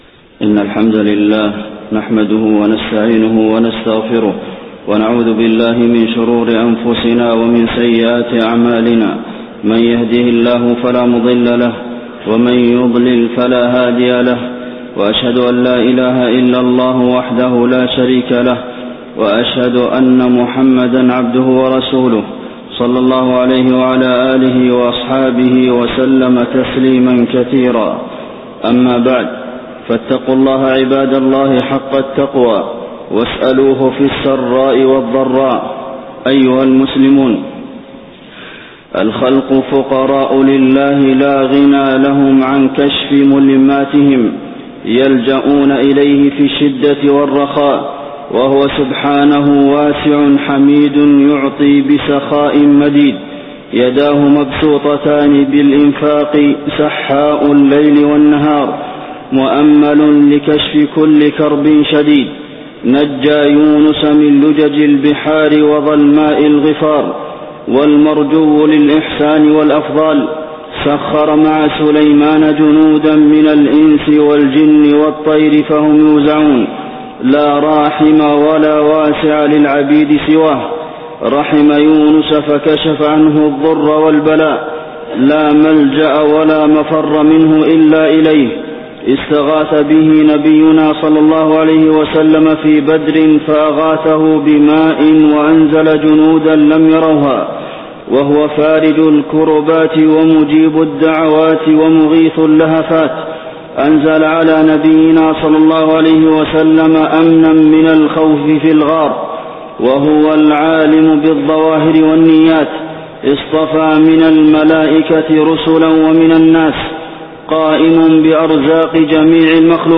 خطبة الاستسقاء - المدينة- الشيخ عبدالمحسن القاسم
المكان: المسجد النبوي